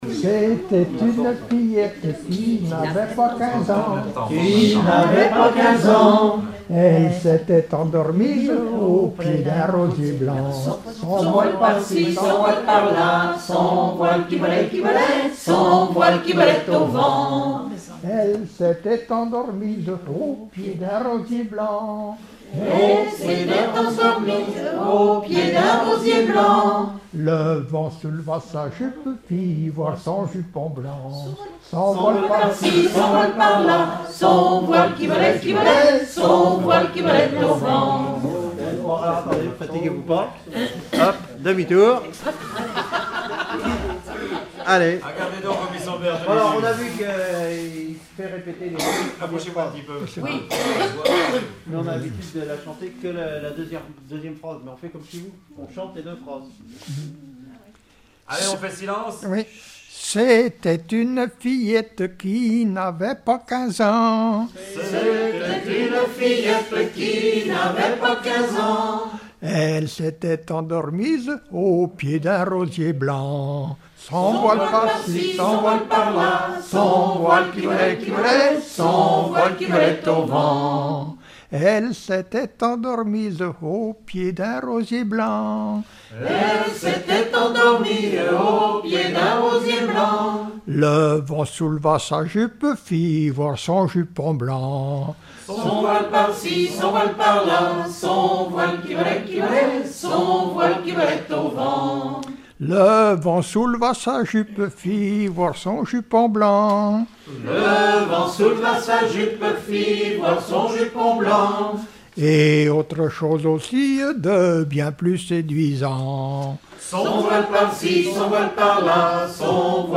Genre laisse
enregistrement d'un collectif lors d'un regroupement cantonal
Pièce musicale inédite